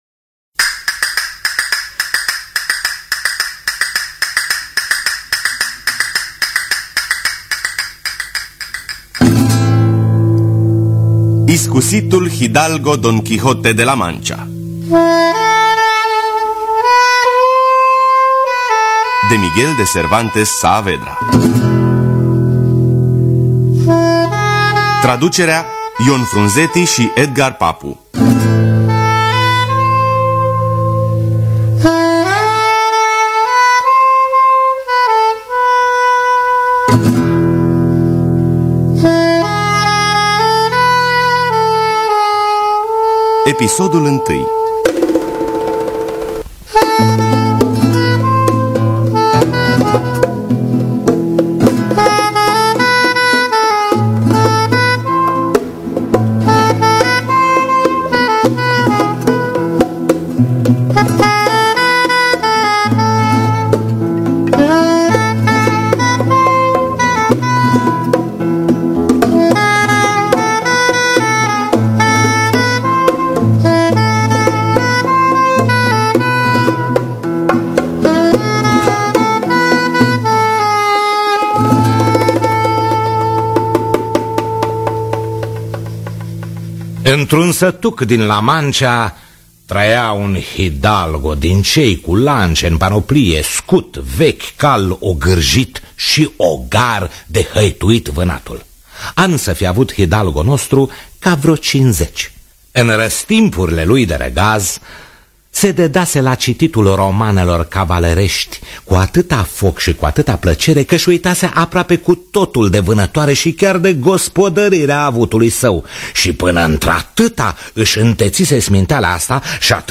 Miguel de Cervantes Saavedra – Iscusitul Hidalgo Don Quijote De La Mancha (2004) – Episodul 1 – Teatru Radiofonic Online
Dramatizarea radiofonică